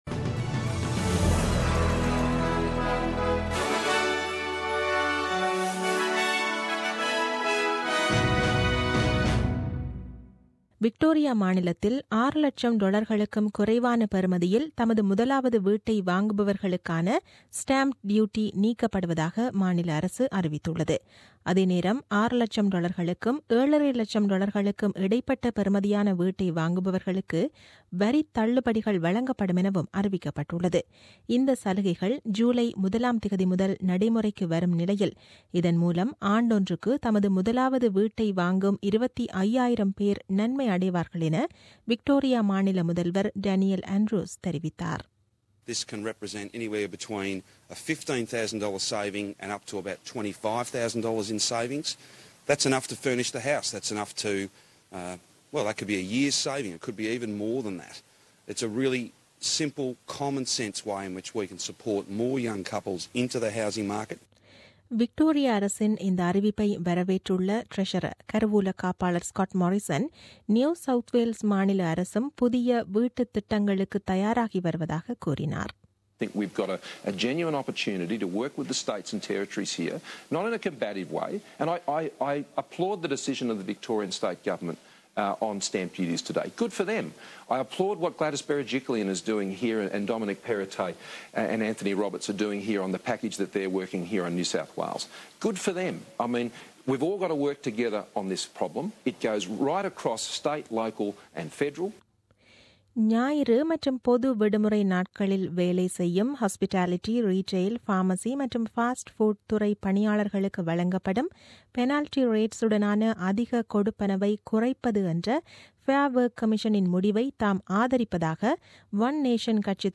The news bulletin aired on 05 Mar 2017 at 8pm.